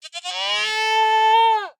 1.21.4 / assets / minecraft / sounds / mob / goat / scream7.ogg
scream7.ogg